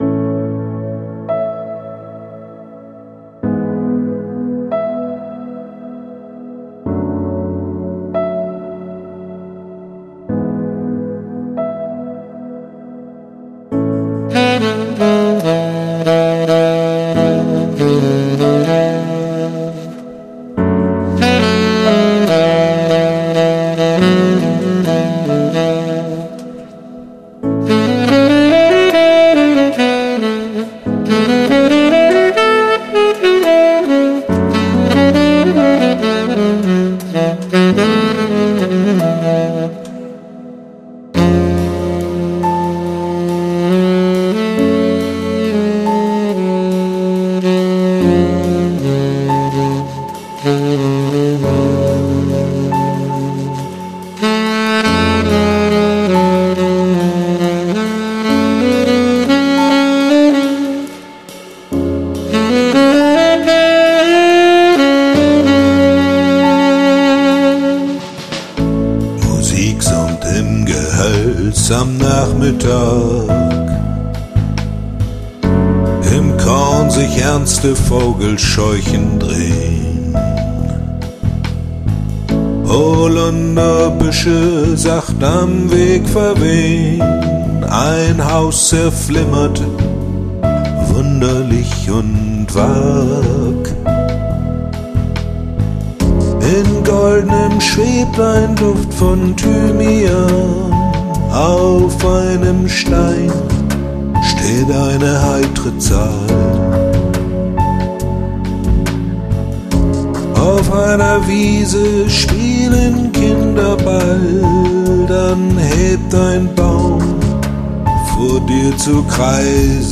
Vertonung eines Gedichtes